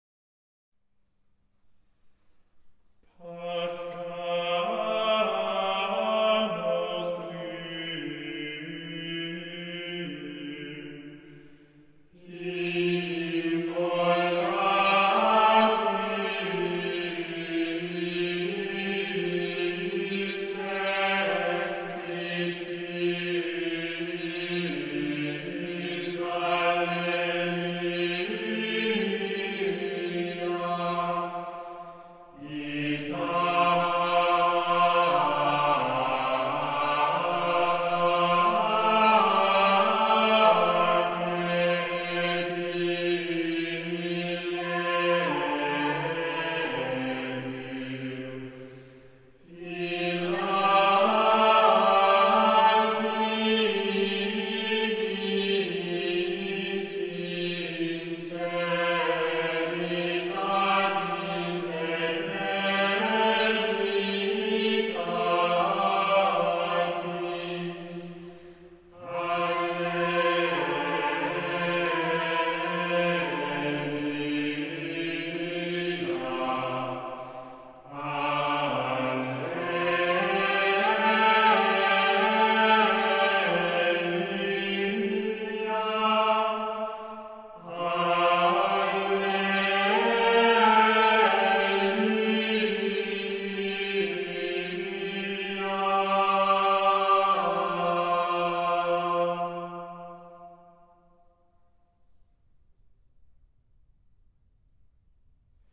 Organum
Choral [100%]
Group: A capella